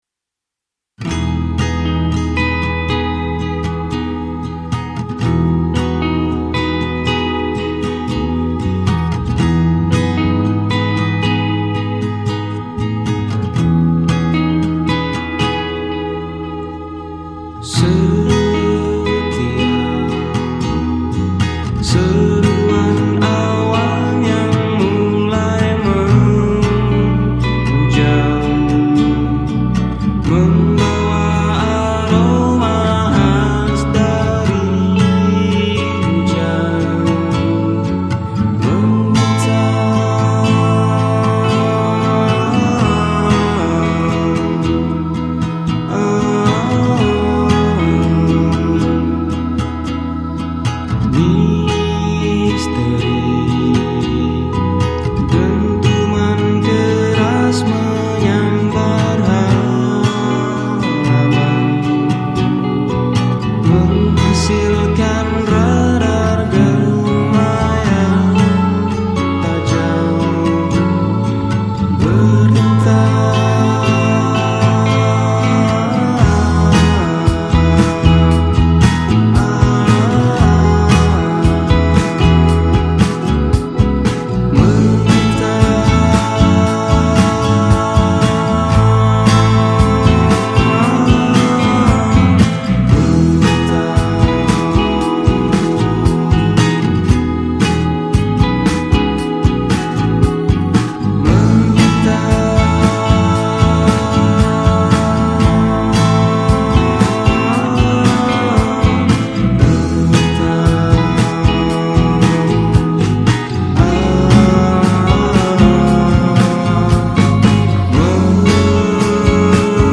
Bekasi Alternative
Sebuah Band dari Bekasi